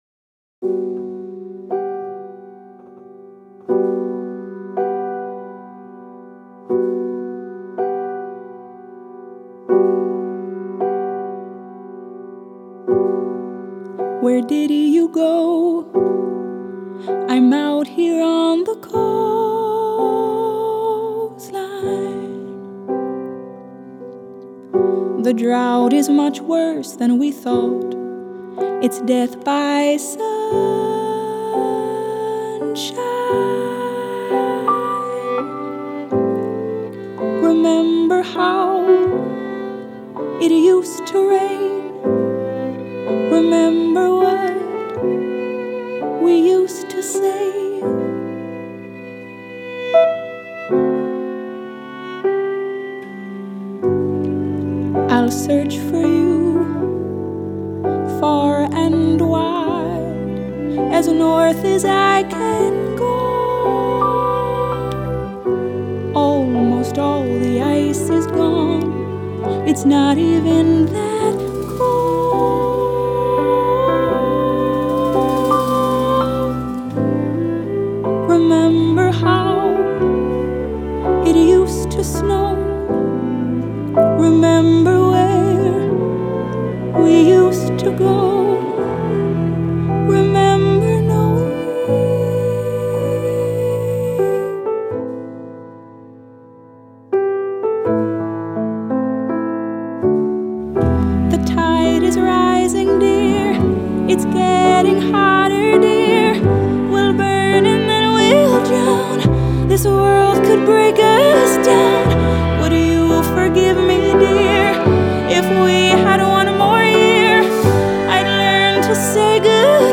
chamber-folk album
Embracing a live performance sound